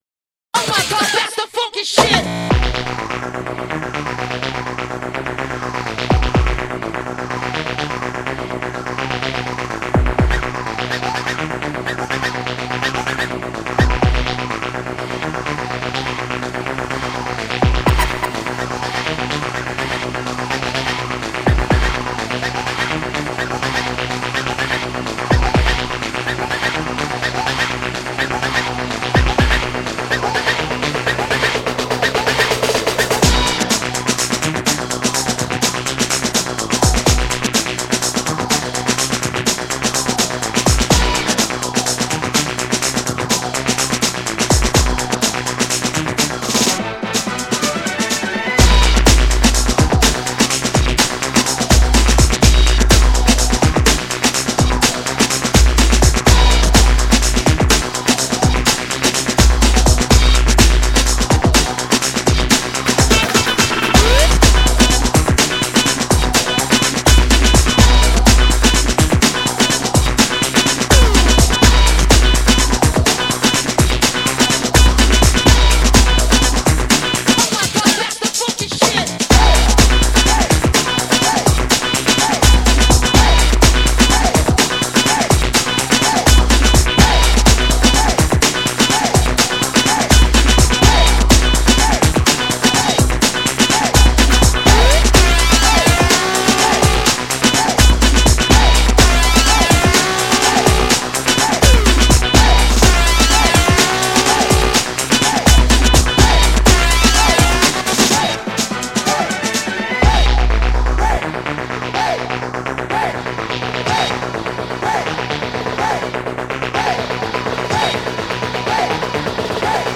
Категория: Progressive House, Electro